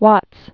(wŏts)